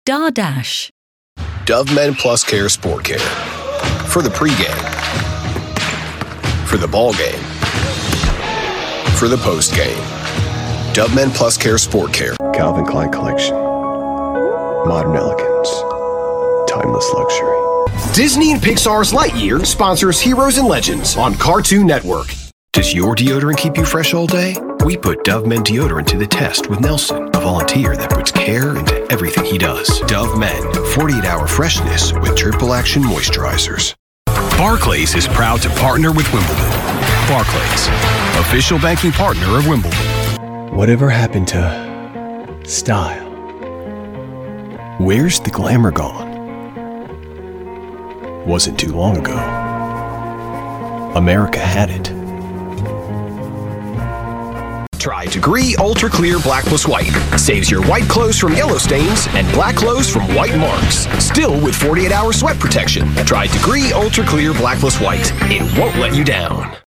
Description: American: young, cool, confident
Age range: 30s - 40s
Commercial 0:00 / 0:00
American*